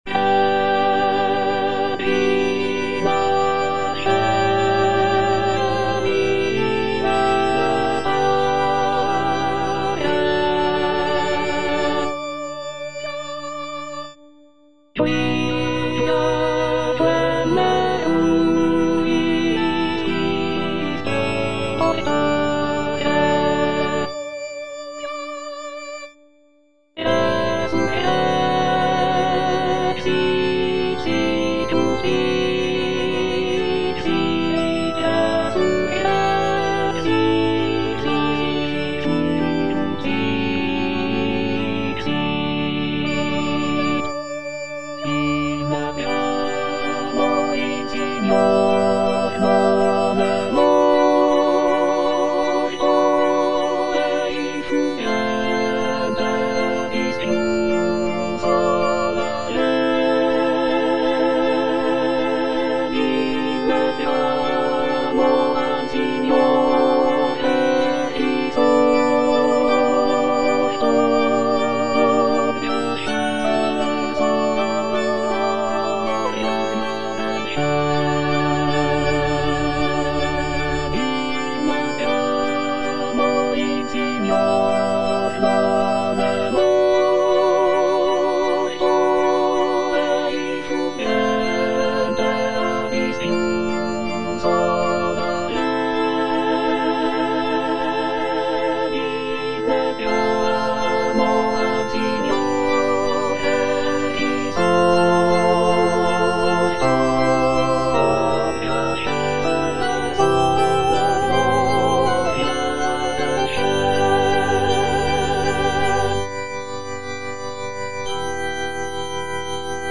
P. MASCAGNI - REGINA COELI DA "CAVALLERIA RUSTICANA" Internal choir, alto (Emphasised voice and other voices) Ads stop: auto-stop
"Regina Coeli" is a choral piece from Pietro Mascagni's opera "Cavalleria Rusticana."
The music is rich and powerful, reflecting the religious fervor and joyous atmosphere of the occasion. The choral harmonies are supported by lush orchestration, creating a grand and uplifting sound.